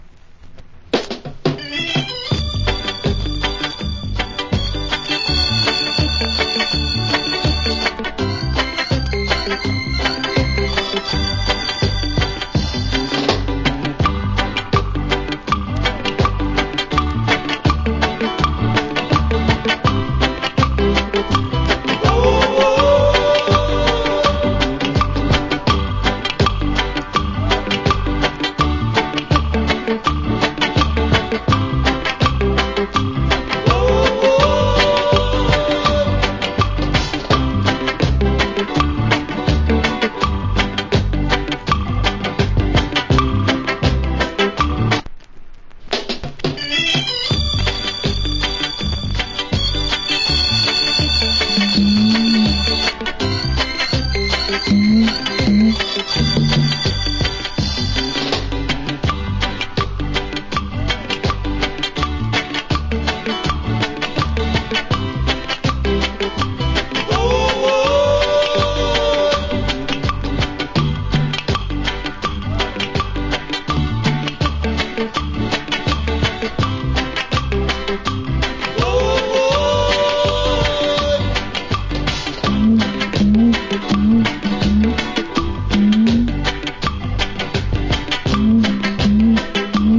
Wicked Reggae Inst.